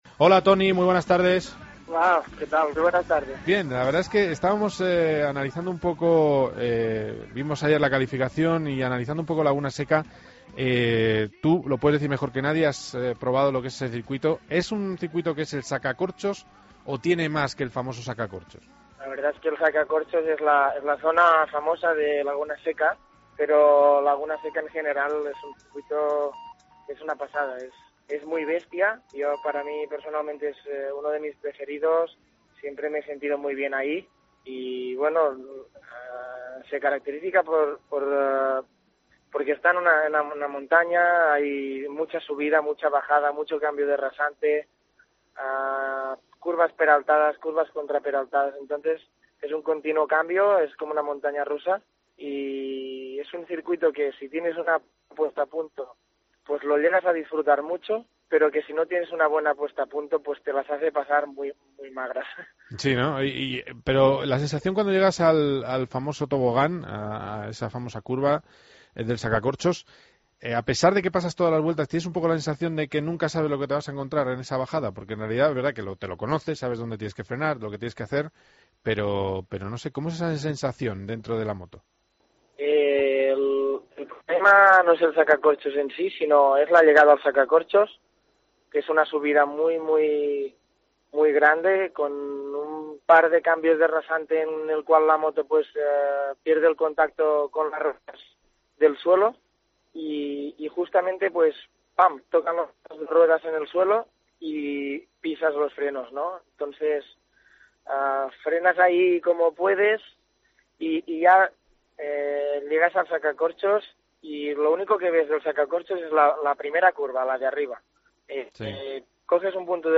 Entrevistamos al piloto de Moto 2 Toni Elias que afirma que el año que viene podría competir en Superbikes